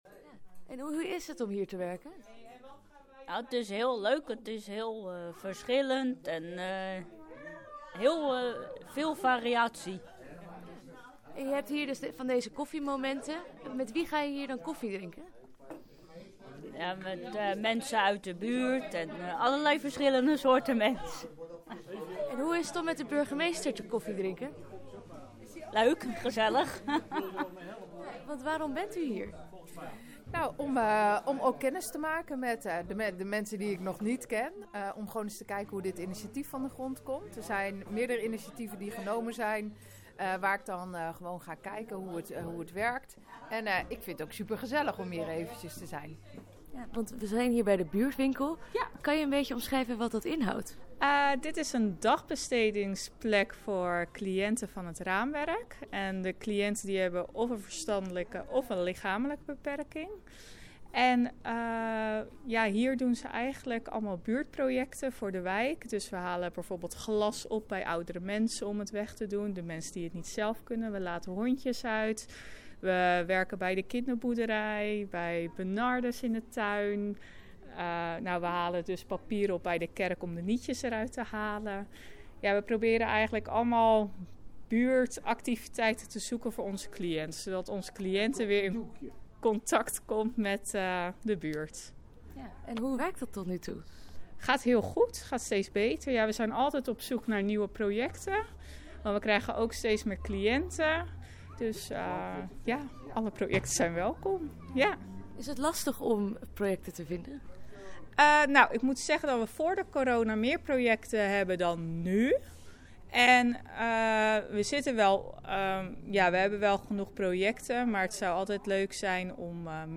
Burgemeester Carla Breuer